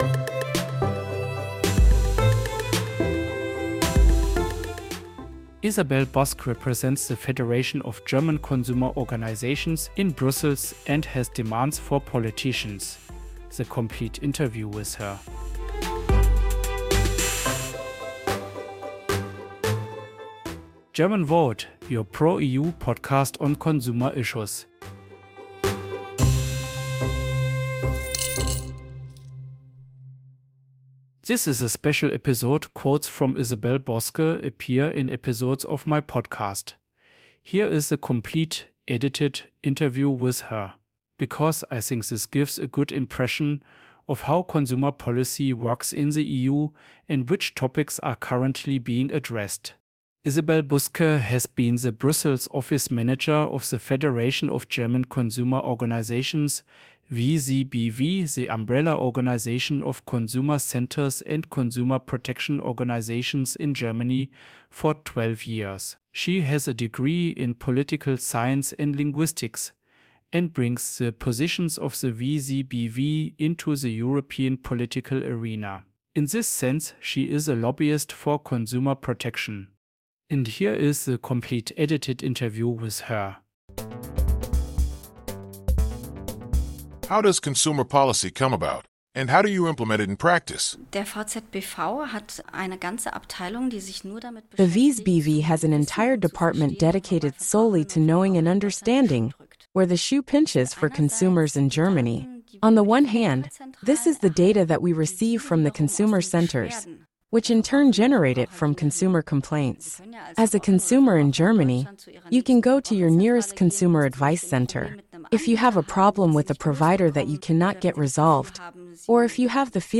The complete interview with her.